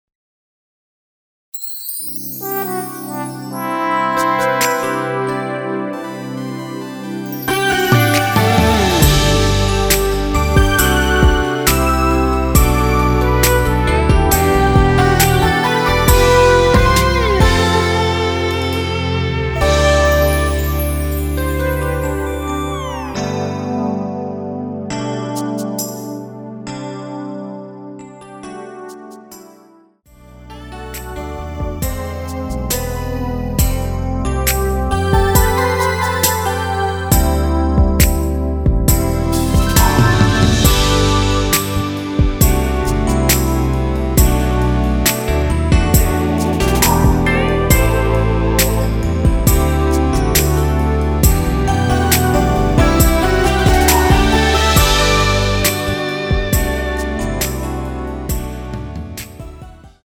원키(짧은편곡) MR입니다.
앞부분30초, 뒷부분30초씩 편집해서 올려 드리고 있습니다.
중간에 음이 끈어지고 다시 나오는 이유는